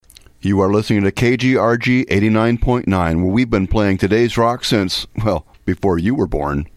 Station Bumper-Since Before You Were Born!